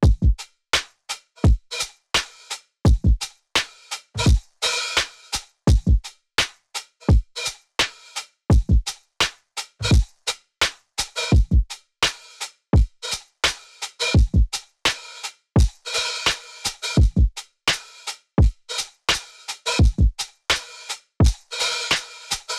AV_Scratch_Drums_85bpm
av_scratch_drums_85bpm